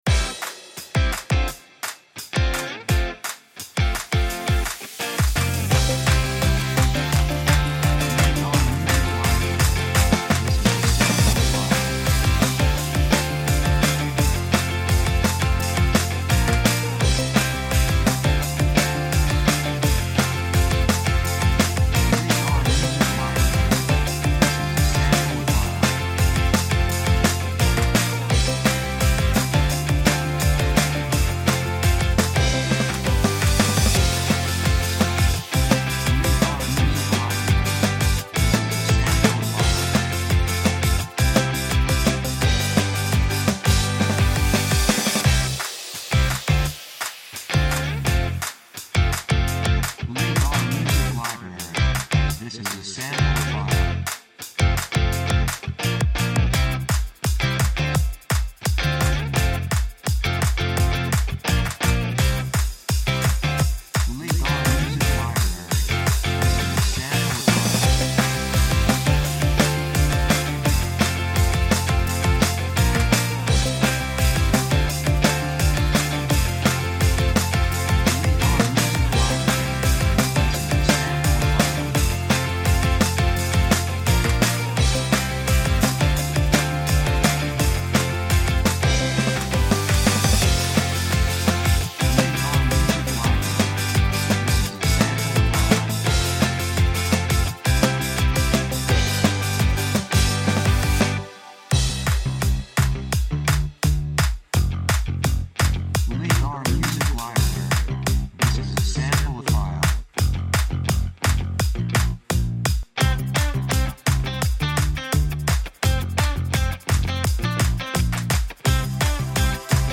2:45 170 プロモ, ロック